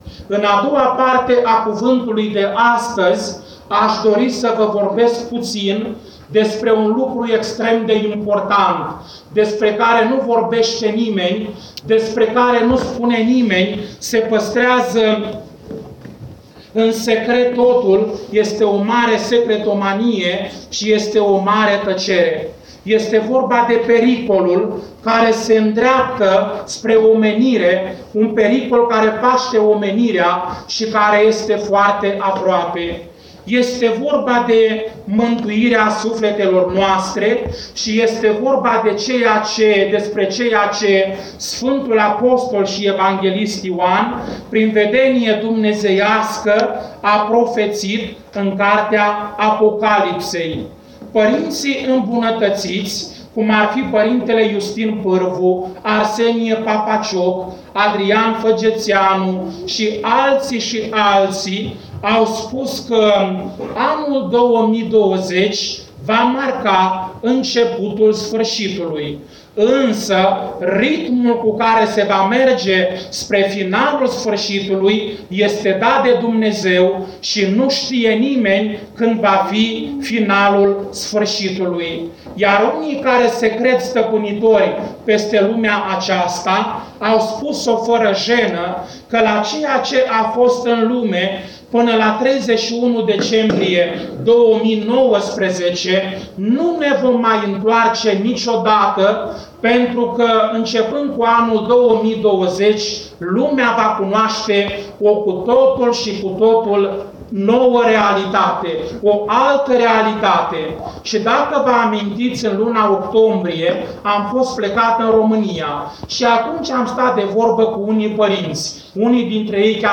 Predici